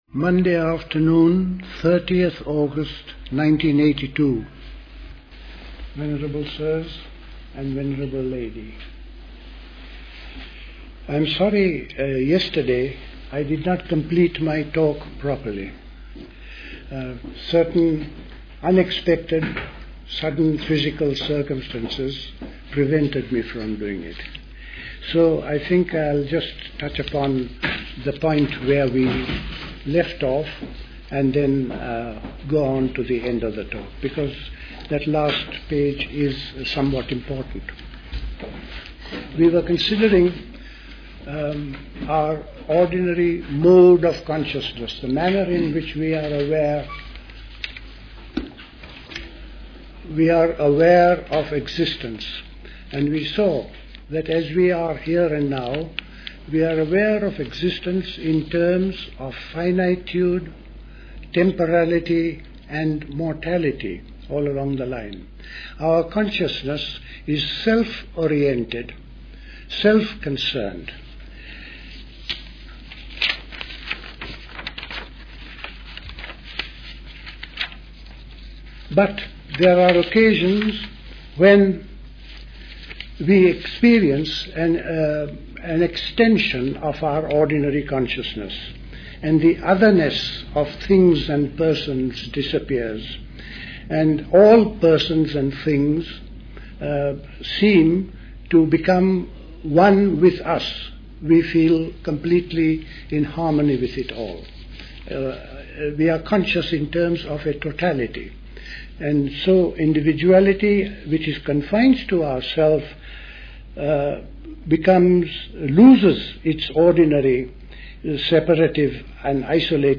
A pause occurs in the middle of this talk.